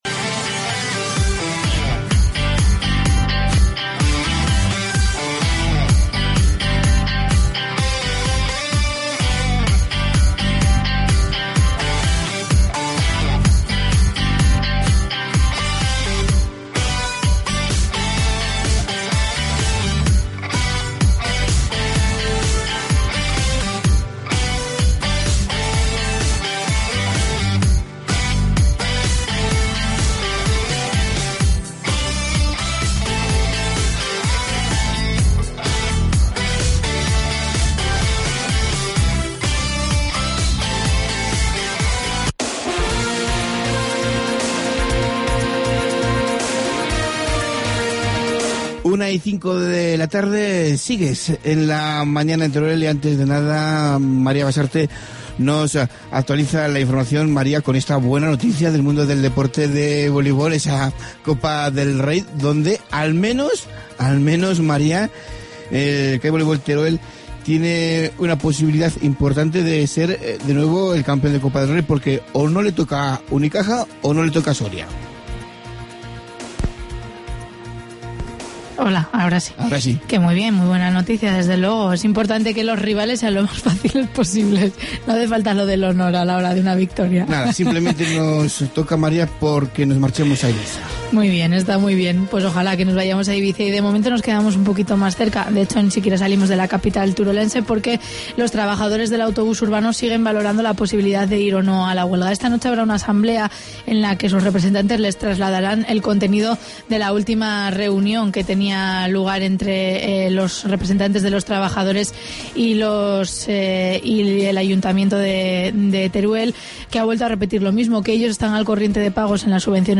Incluye el espacio musical Da Cope.